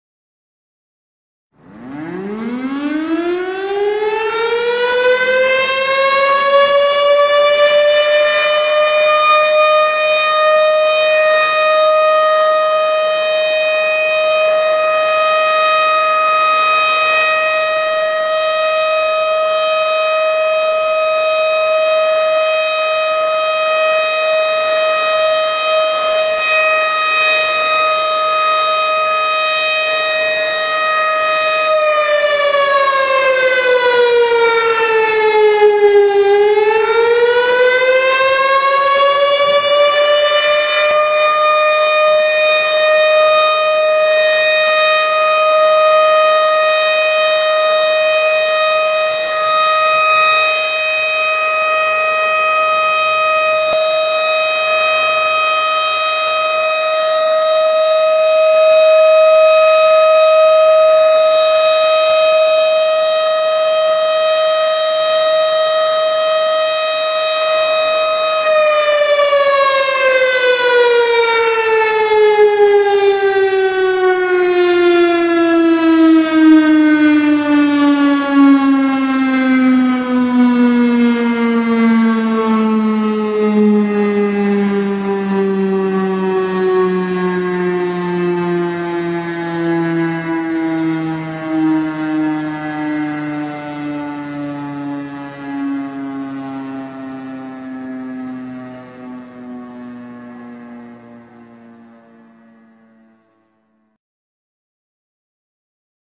Keterangan: Unduh alarm Sirine Imsak dalam format MP3.